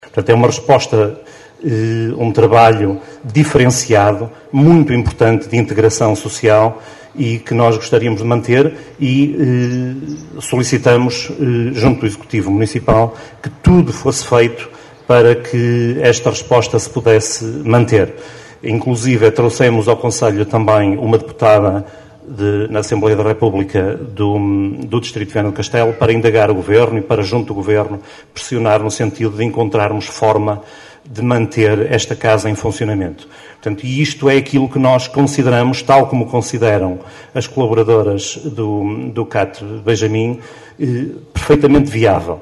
Cerca de meia centena de pessoas reuniram-se ontem ao final da tarde em Caminha para uma vigília a favor da manutenção do Centro de Acolhimento Temporário Benjamim (CAT) de Seixas, cujo encerramento por parte da APPACDM, está previsto para finais de junho.
O vereador do PSD, José Presa, que também marcou presença nesta vigília, apelou à manutenção do CAT, considerando aquela reposta social fundamental para a integração dos jovens.